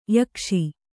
♪ yakṣi